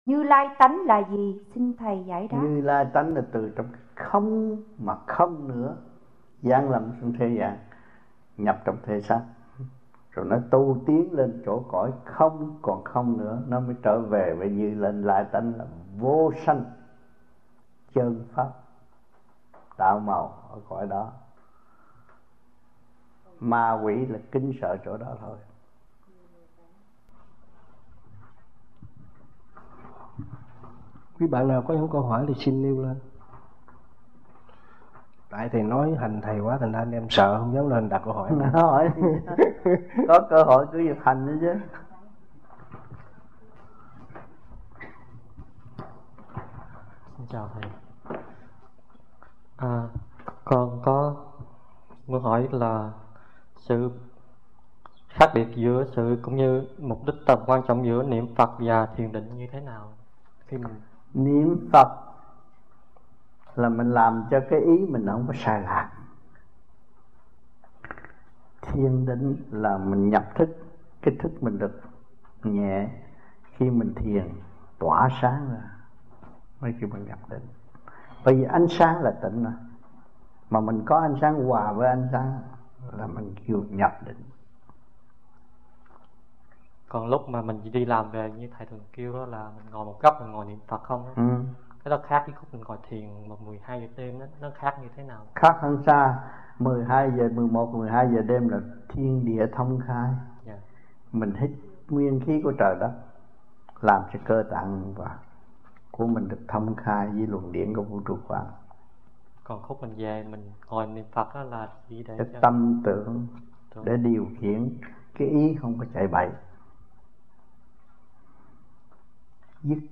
1995-11-07 - THIỀN VIỆN HAI KHÔNG - THUYẾT PHÁP 2